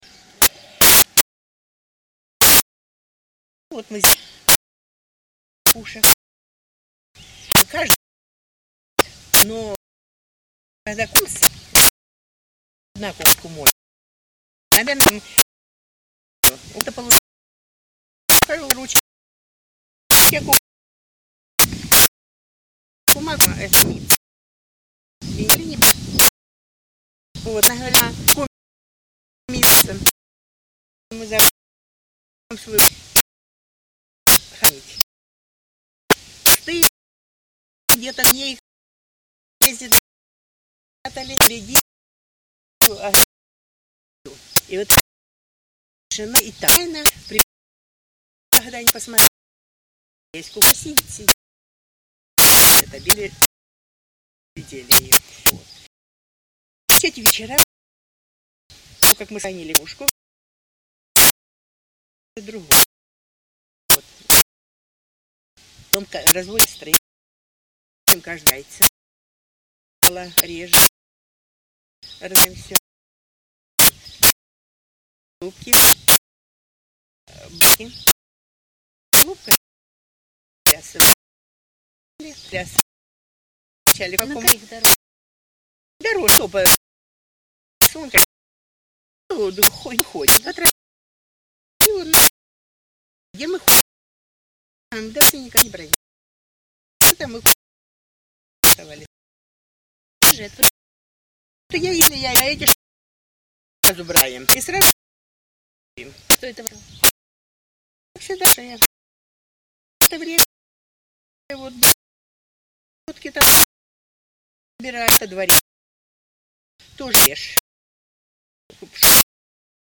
Белгородская область, Валуйский район, село Тимоново
Рассказ